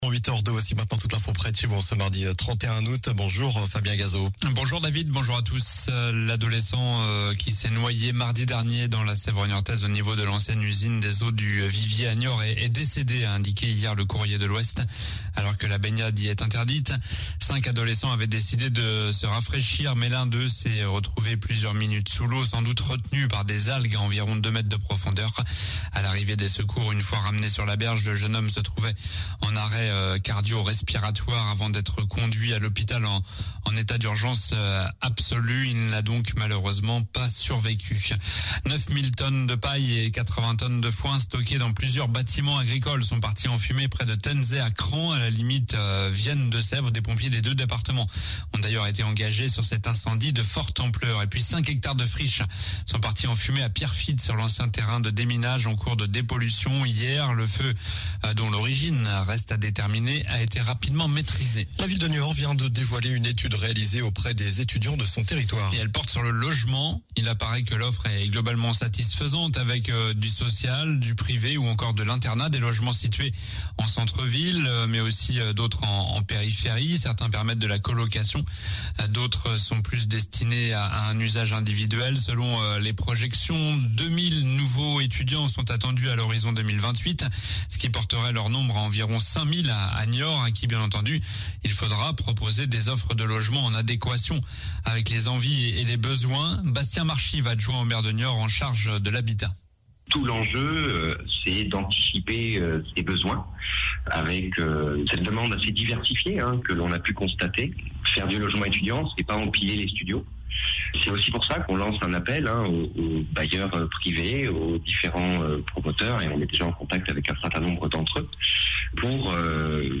Journal du mardi 31 août (matin)